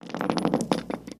grenade_roles_in2.ogg